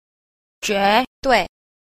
1. 絕對 – juéduì – tuyệt đối